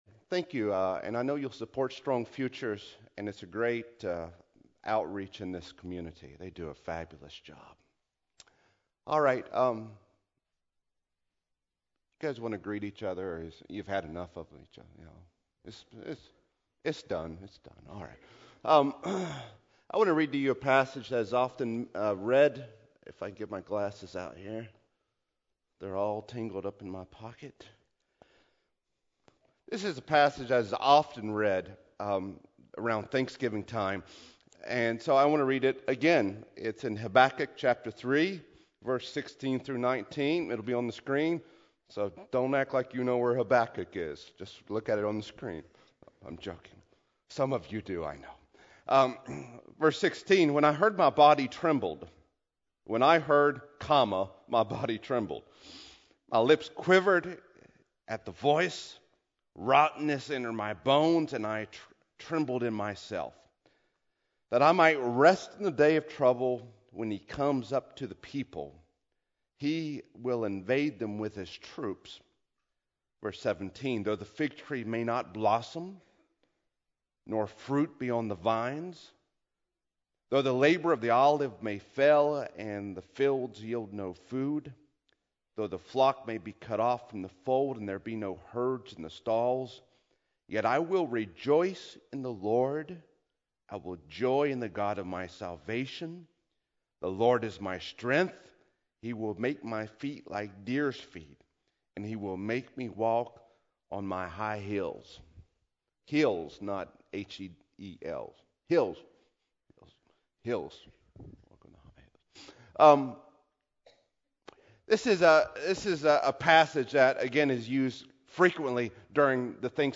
AM Venue: Greeneville First Church of God Service Type: Regular Service Scripture